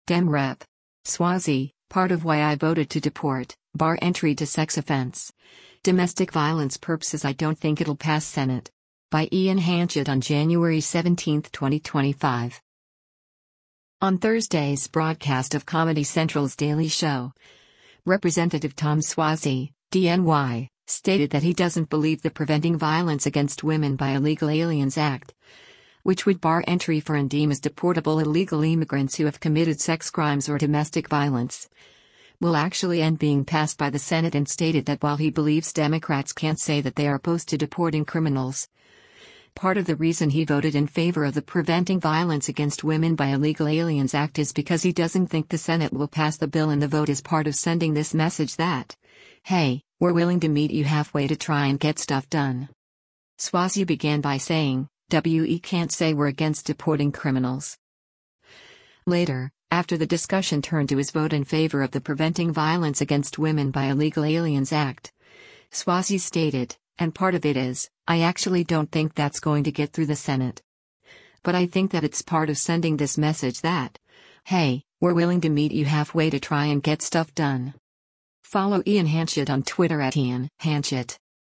On Thursday’s broadcast of Comedy Central’s “Daily Show,” Rep. Tom Suozzi (D-NY) stated that he doesn’t believe the Preventing Violence Against Women by Illegal Aliens Act, which would bar entry for and deem as deportable illegal immigrants who have committed sex crimes or domestic violence, will actually end being passed by the Senate and stated that while he believes Democrats can’t say that they are opposed to deporting criminals, part of the reason he voted in favor of the Preventing Violence Against Women by Illegal Aliens Act is because he doesn’t think the Senate will pass the bill and the vote is “part of sending this message that, hey, we’re willing to meet you halfway to try and get stuff done.”